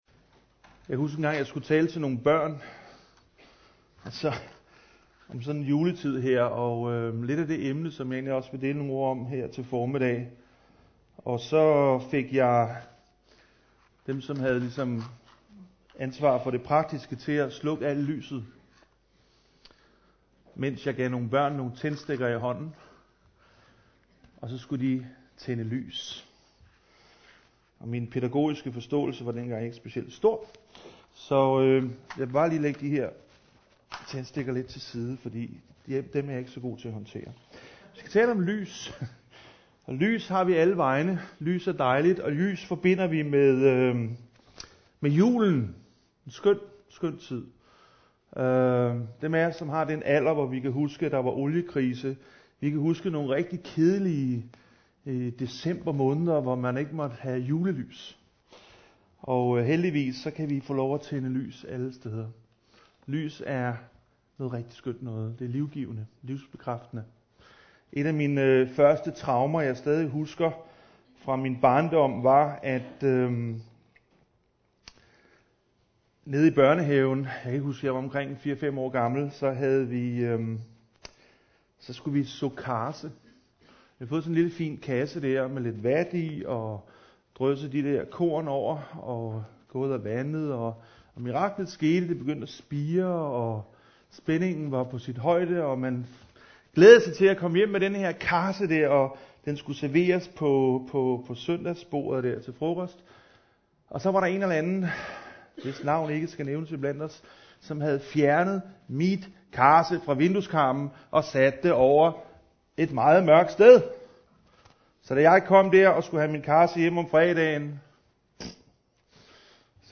14. december 2014 Type af tale Prædiken Bibeltekst Johannes Evangeliet MP3 Hent til egen PC